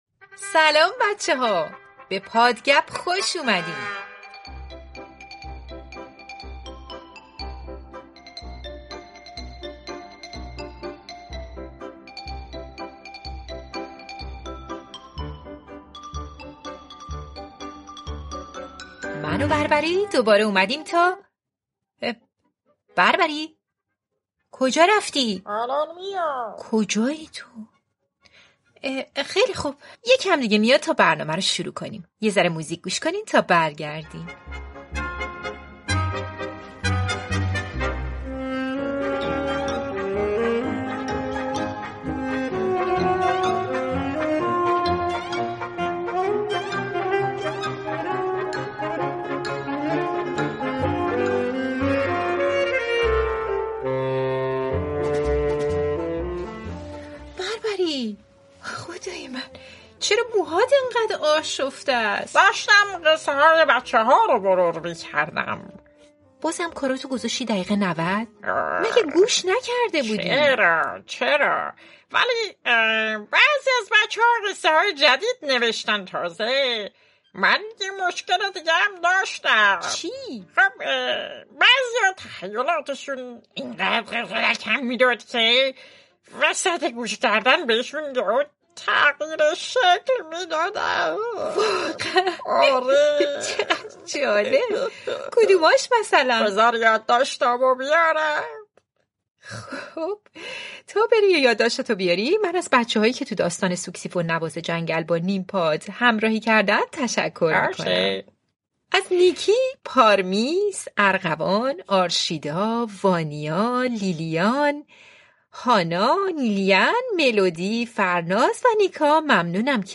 پادکست «هاچین واچین» اولین کتاب صوتی، مجموعه داستان‌های کودکان است.